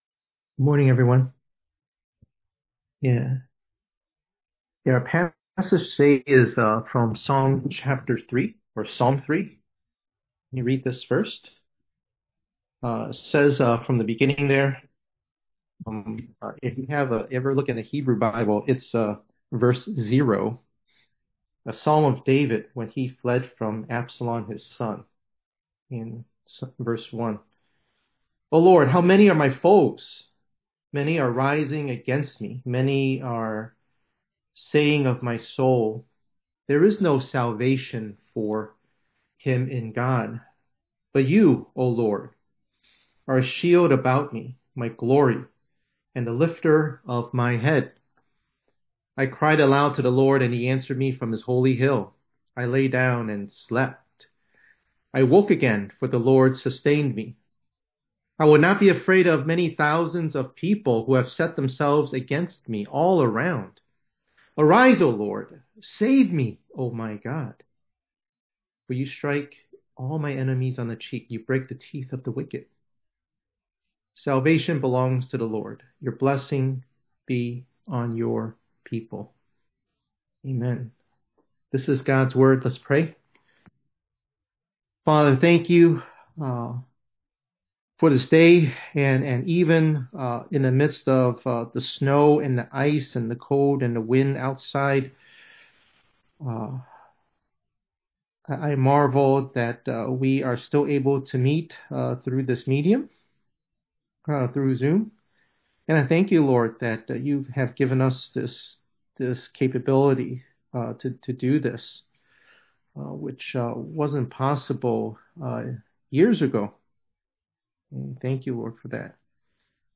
1_25_26_ENG_Sermon.mp3